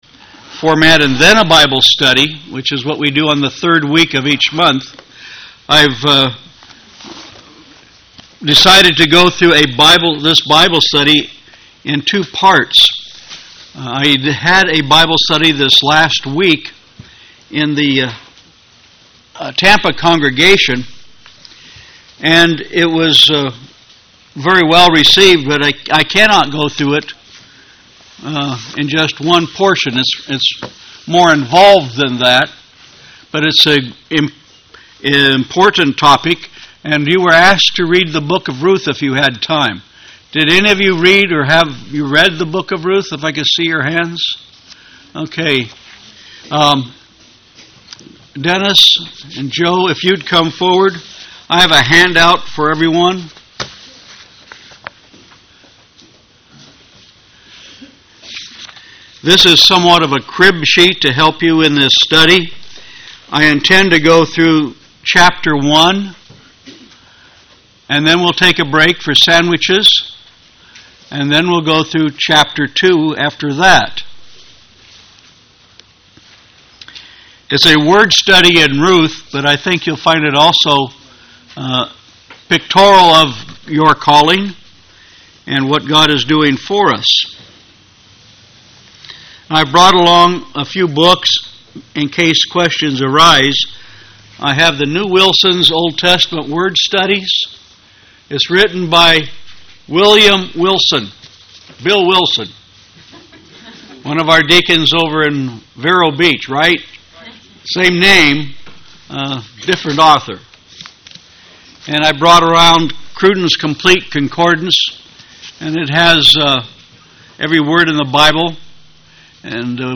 Bible Study of the book of Ruth by Chapter.
Given in St. Petersburg, FL
UCG Sermon Studying the bible?